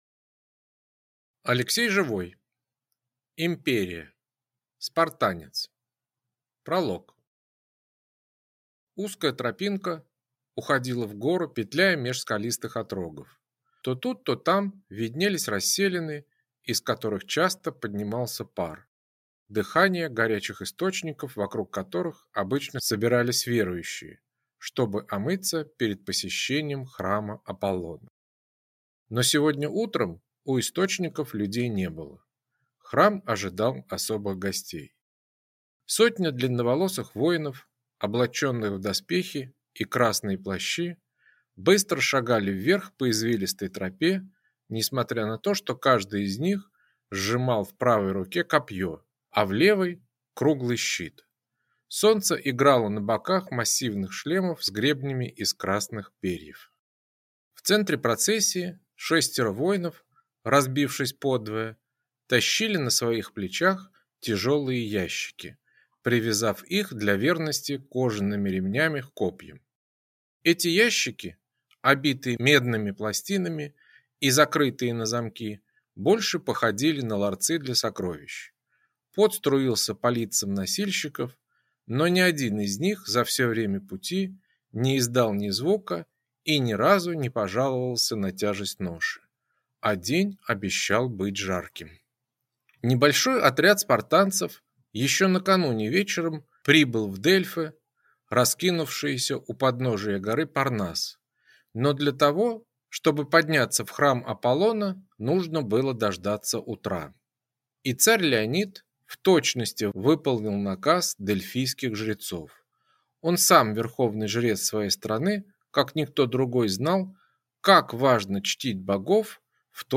Аудиокнига Спартанец | Библиотека аудиокниг